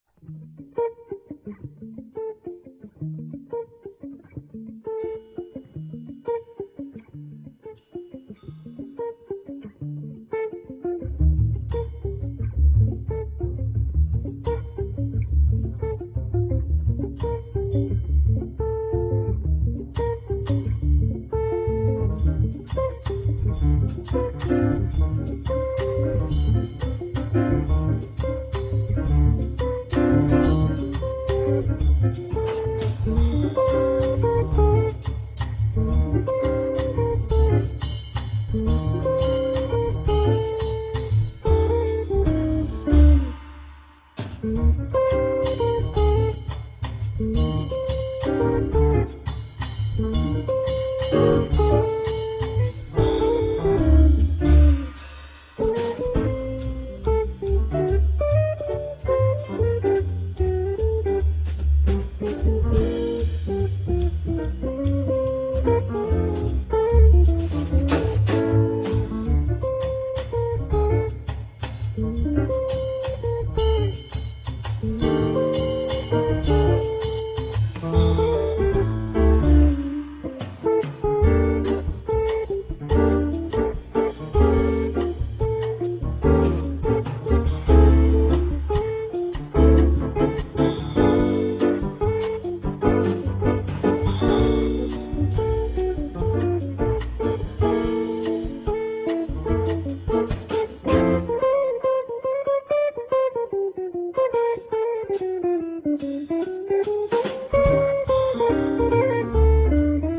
piano digitale
la parte B è arrangiata a ritmo di bossa nova
Radio quality 1'50'' 278 K
My jazz 4tet